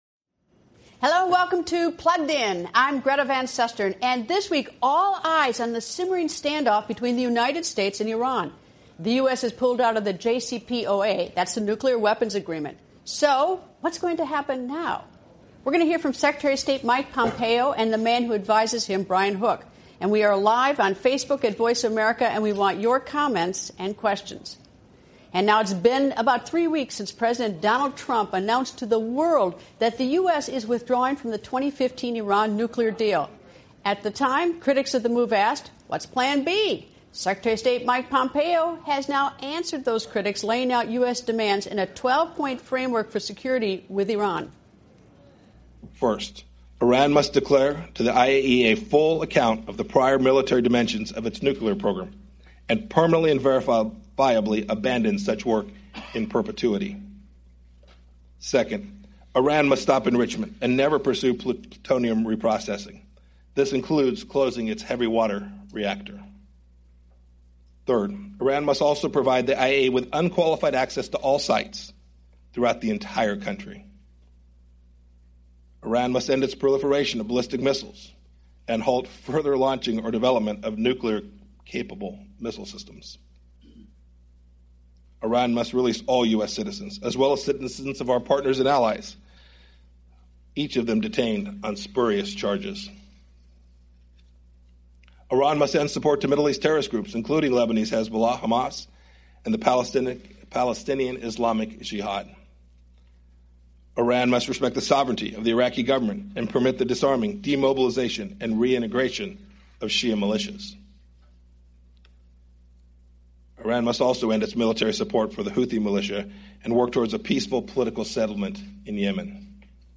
What’s next, now that the U.S. has withdrawn from the Iran nuclear agreement? Secretary of State Mike Pompeo outlines the 12-point framework for security that the U.S. will pursue to replace the Iran nuclear deal. And Greta interviews Brian Hook, a senior policy adviser at the State Department.